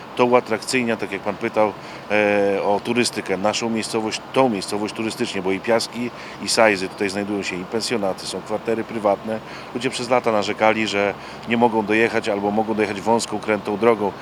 – Okoliczne tereny będą częściej odwiedzane przez turystów – cieszy się Tomasz Osewski, wójt gminy Ełk.